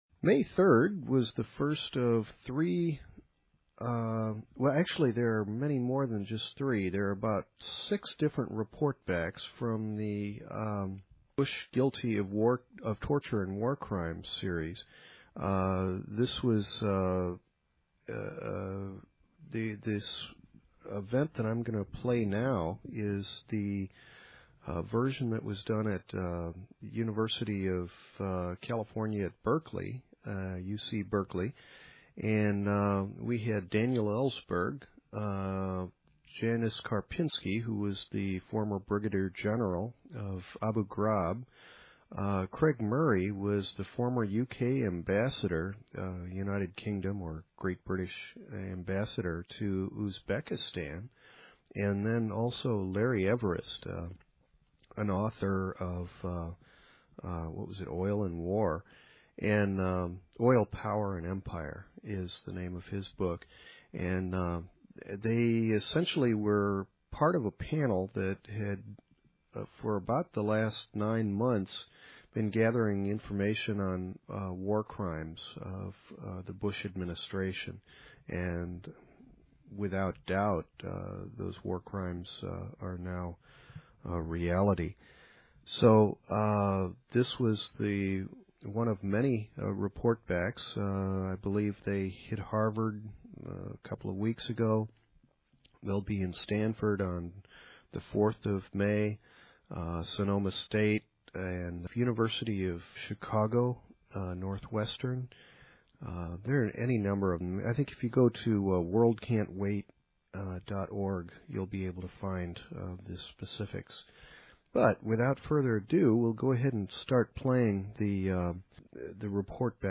Bush Crimes Commission Tour - UC Berkeley
May 3, 2006 - UC Berkeley Speakers in order of appearance: